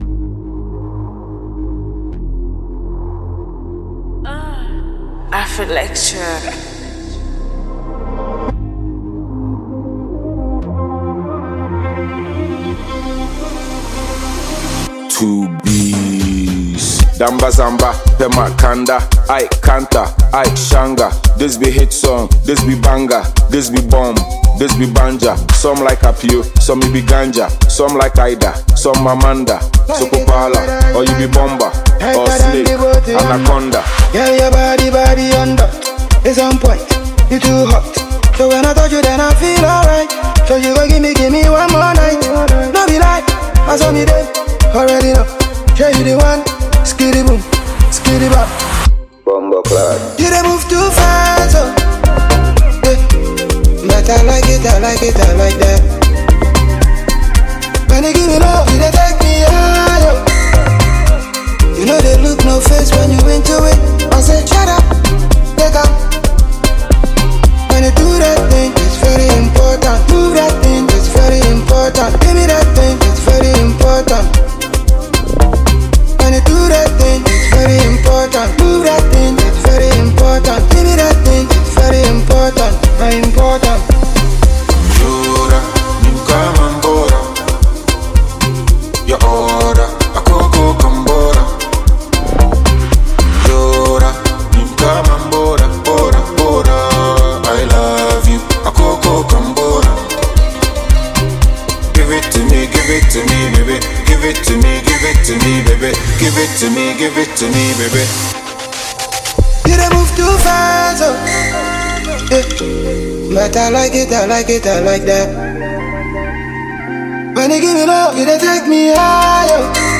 Ghanaian multiple award-winning duo